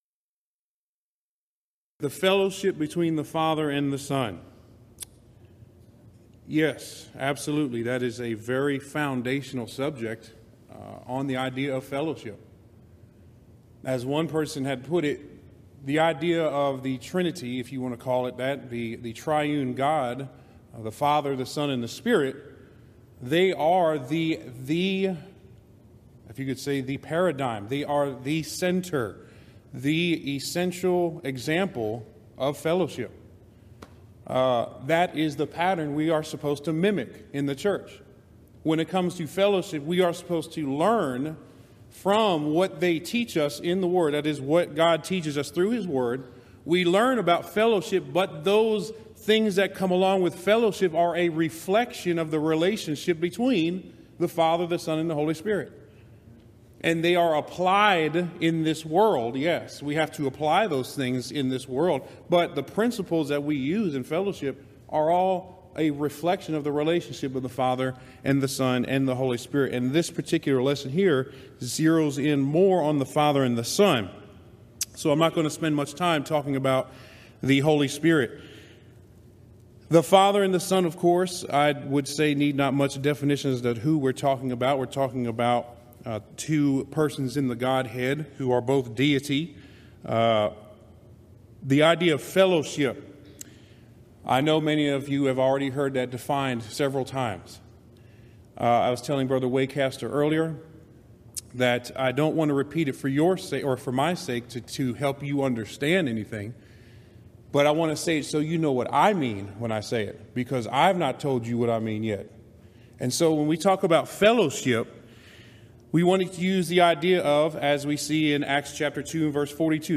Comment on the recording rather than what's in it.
Event: 24th Annual Gulf Coast Lectures Theme/Title: Christian Fellowship